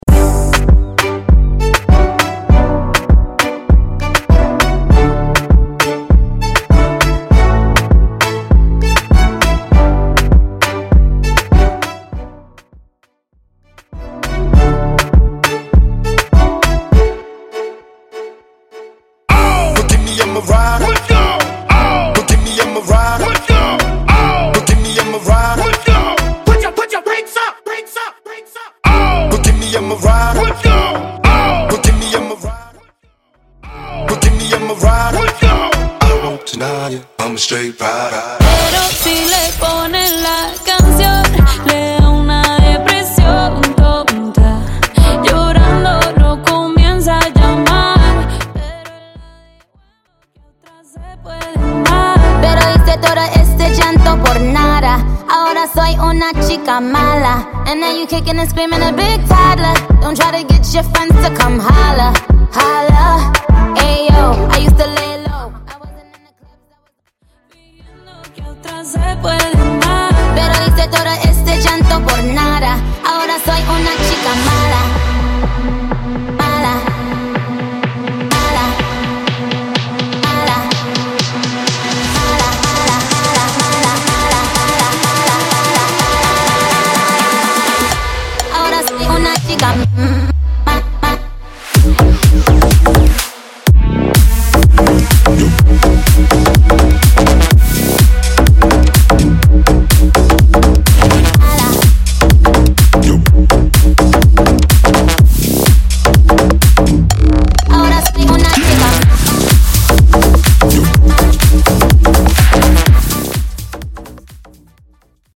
FUTURE HOUSE , PARTY BREAKS , TOP40 125